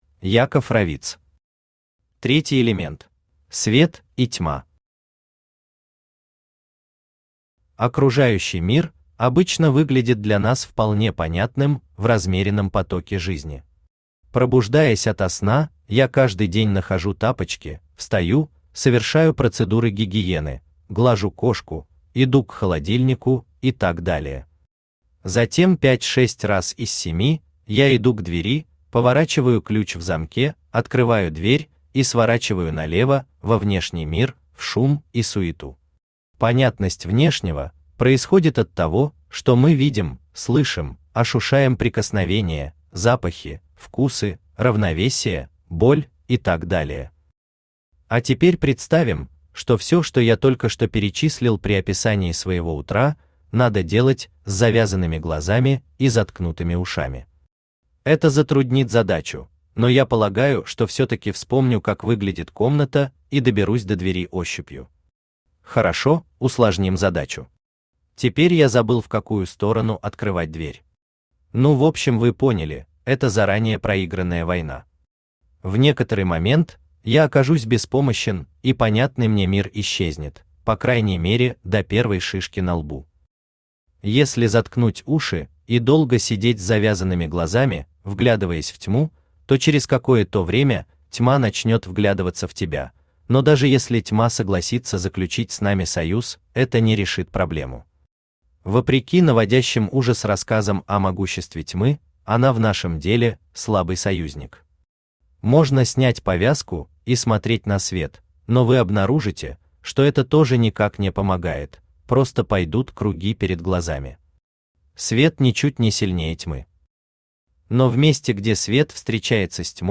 Аудиокнига Третий Элемент | Библиотека аудиокниг
Aудиокнига Третий Элемент Автор Яков Романович Равиц Читает аудиокнигу Авточтец ЛитРес.